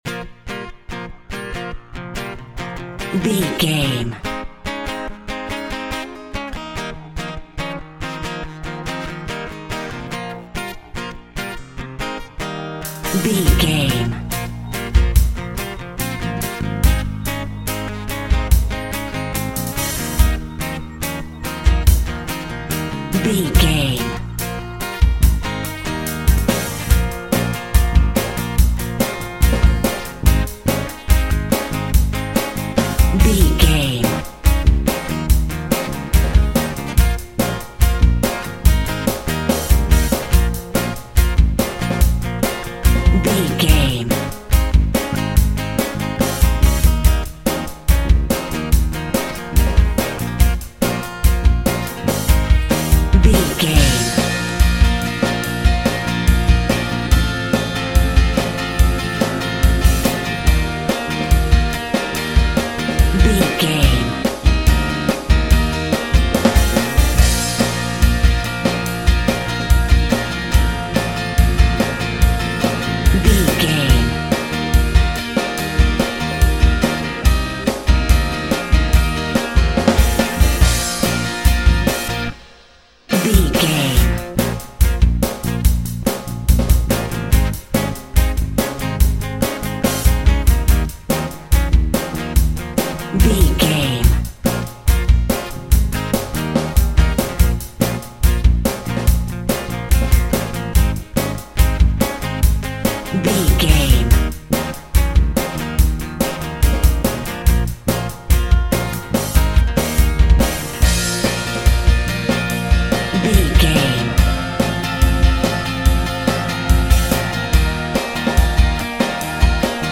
Driving Rock.
Fast paced
Ionian/Major
B♭
blues rock
distortion
hard rock
Instrumental rock
drums
bass guitar
electric guitar
piano
hammond organ